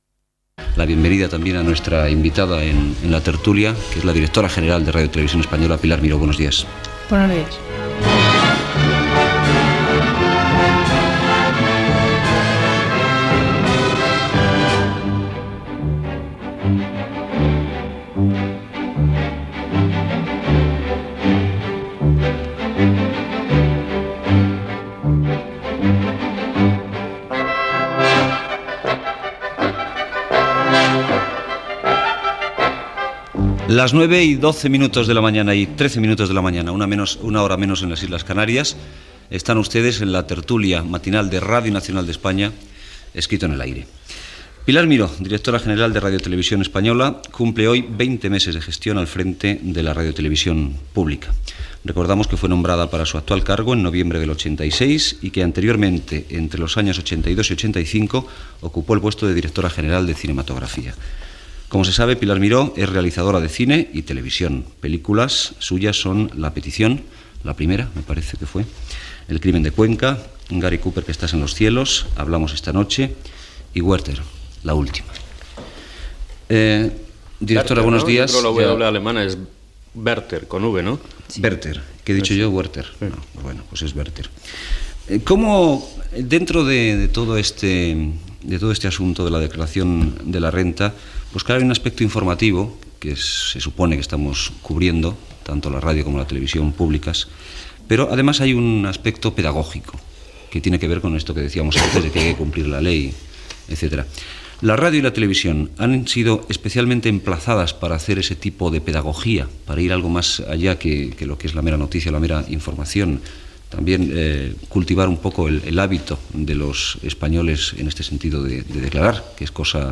Entrevista a Pilar Miró, directora general de RTVE. S'hi parla de la declaració de la renda, la publicitat a la televisió i la ràdio pública